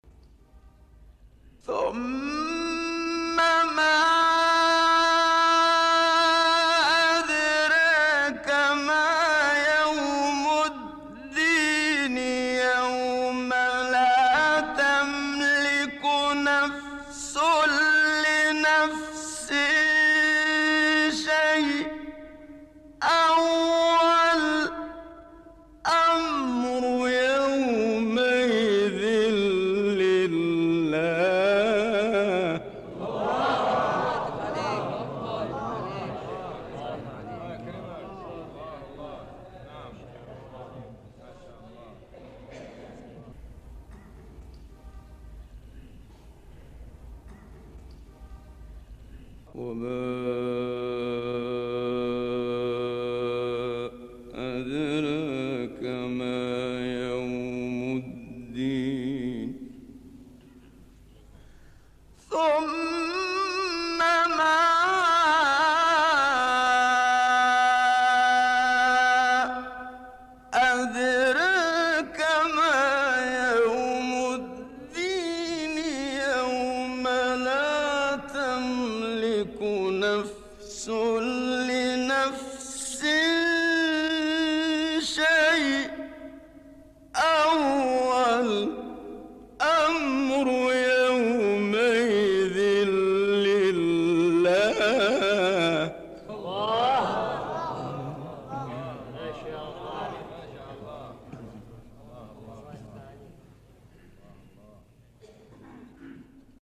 قطعات شنیدنی از تلاوت سوره مبارکه انفطار را با صوت قاریان محمد اللیثی، شحات محمد انور، عبدالباسط محمد عبدالصمد، محمد صدیق منشاوی و راغب مصطفی غلوش می‌شنوید.
آیات ۱۷ تا ۱۹ سوره انفطار با صوت محمد صدیق منشاوی